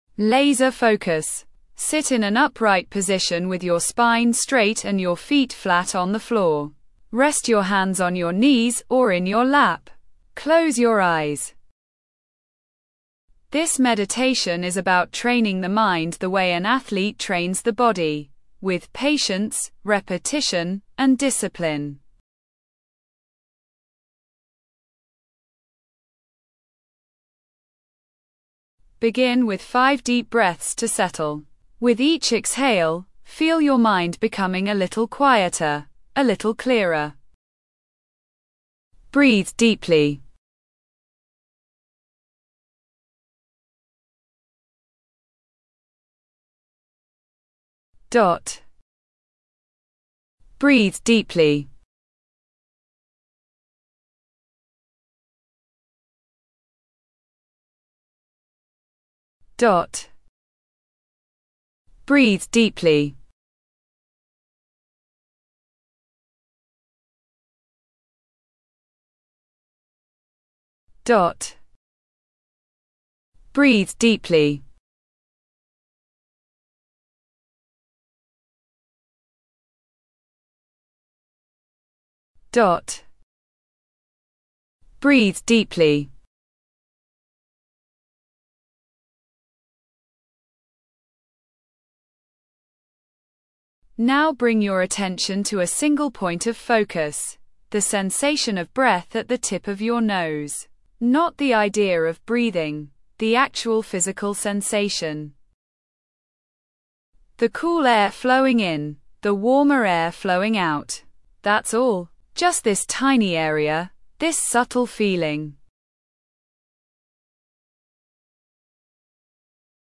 Laser Focus: A Meditation for Concentration and Mental Clarity